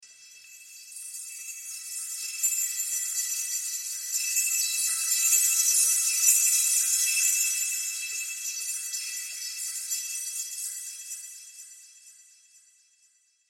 دانلود آهنگ شب 7 از افکت صوتی طبیعت و محیط
دانلود صدای شب 7 از ساعد نیوز با لینک مستقیم و کیفیت بالا
جلوه های صوتی